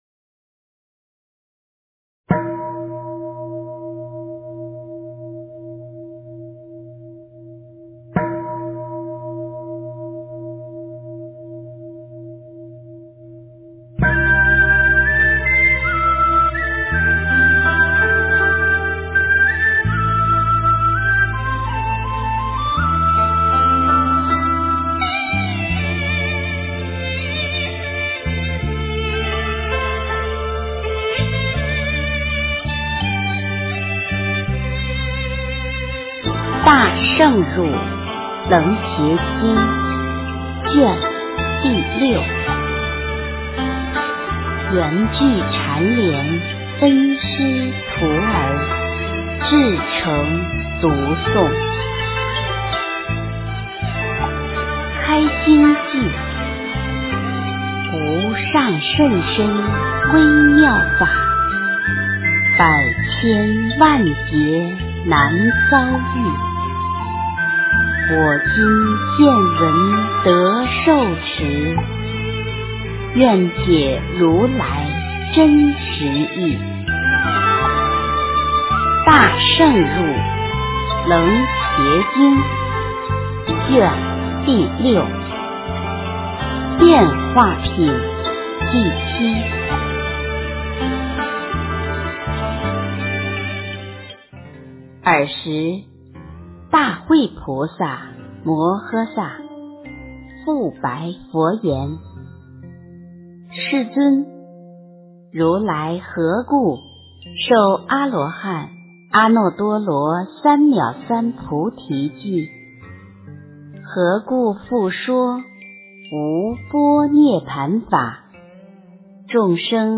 诵经